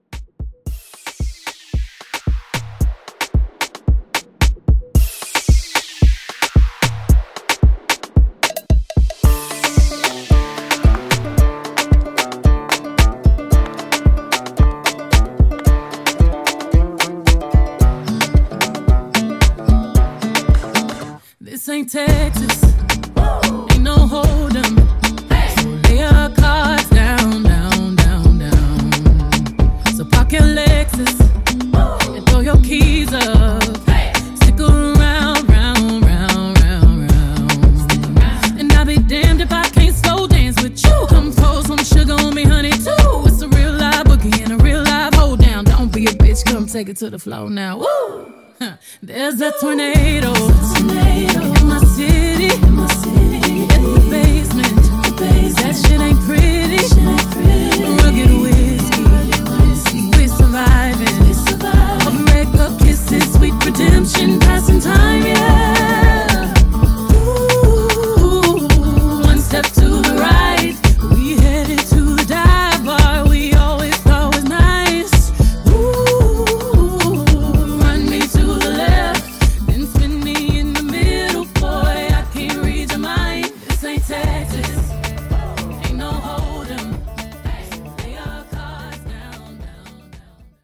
Genres: MOOMBAHTON , RE-DRUM , TOP40
Clean BPM: 95 Time